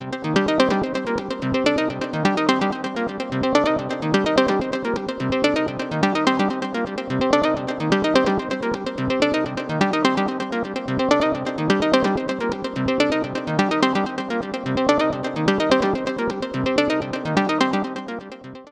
The synth loop with side chaining applied.